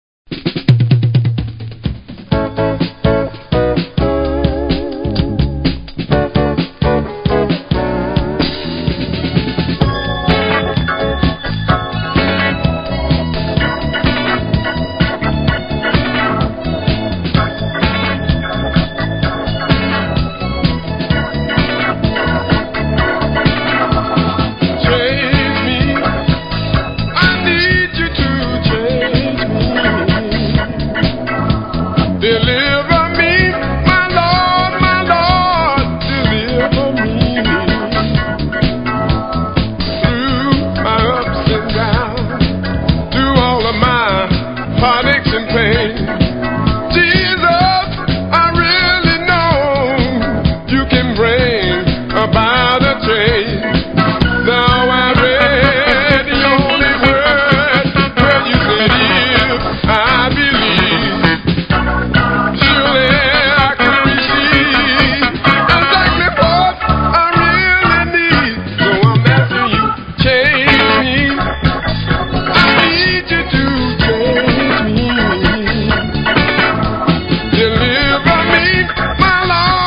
Unknown 70's dancer